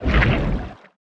Sfx_creature_spikeytrap_pulling_02.ogg